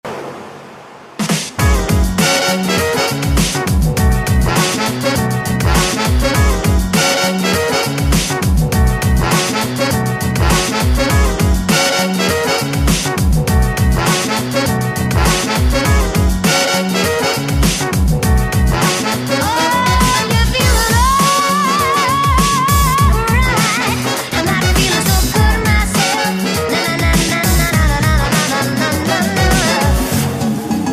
w Pop / Soul
Może to jakiś remix, albo nightcore - nie mam pojęcia.